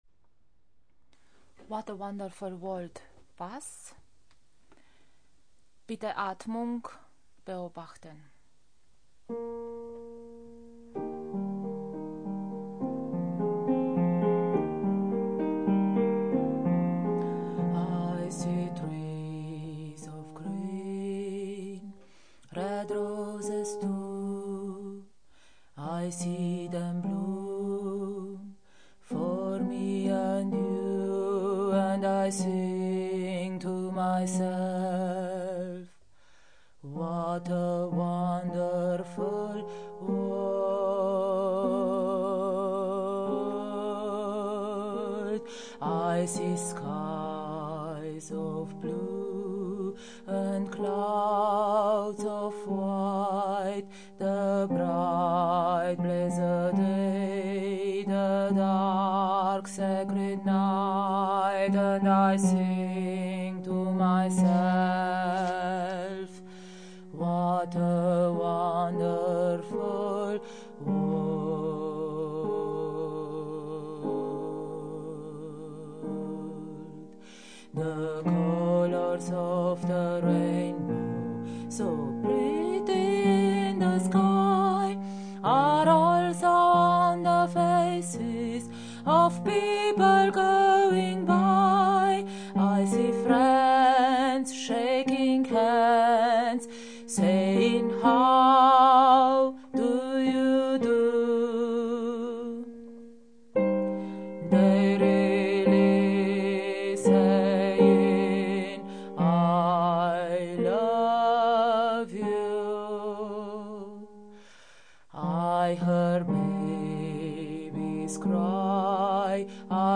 What a wonderful world Bass
Bass_What_a_wonderful_world.mp3